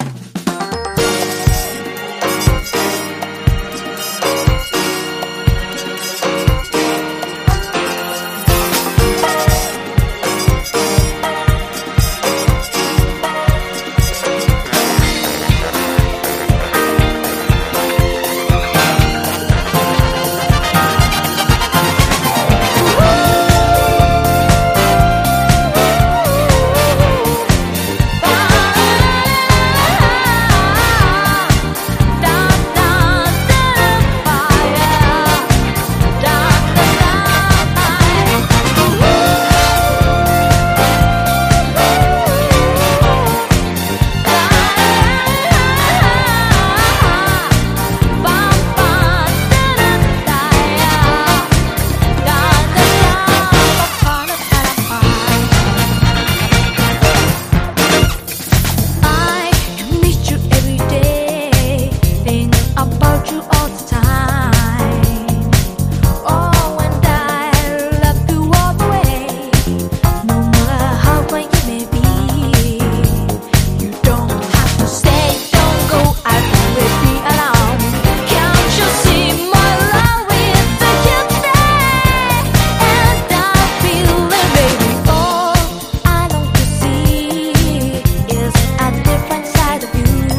国産90’S R&B最重要曲！